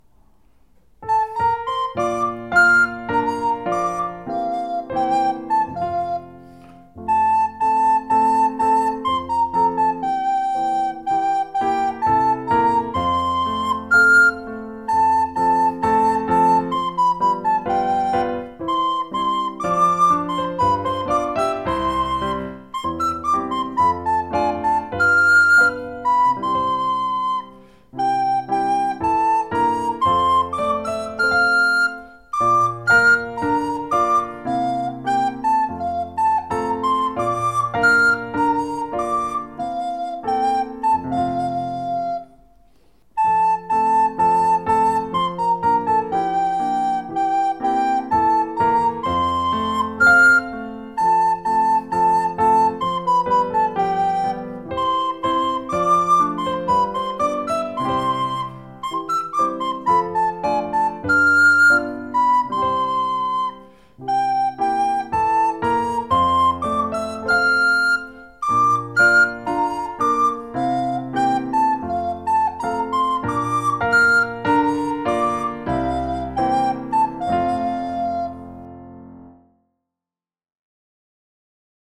HEIDENROSLEIN-piano-y-flauta-WAV.wav